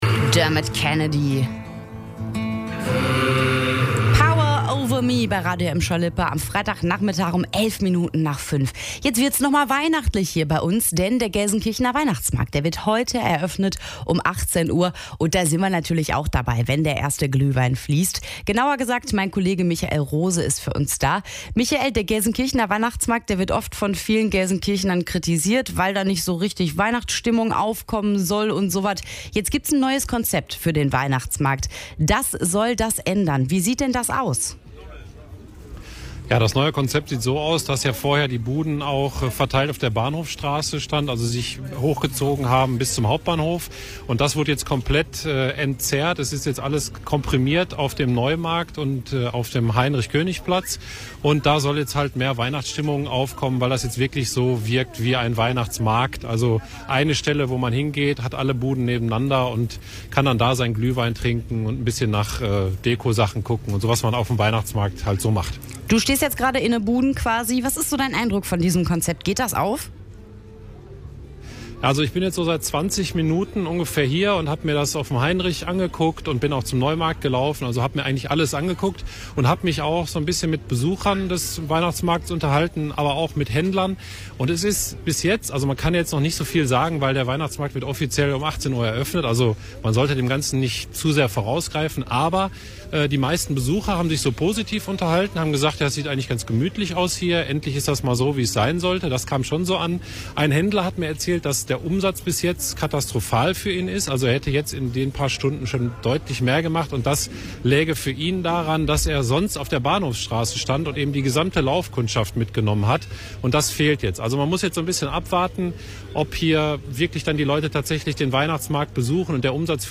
Ende November wird es langsam aber sicher weihnachtlich, denn die Weihnachtsmärkte starten nach und nach. Heute war dann auch Gelsenkirchen dran und unserer Reporter war mit dabei und hat sich live in die Sendung gemeldet.
weihnachtsmarkt-ge-eroeffnung.mp3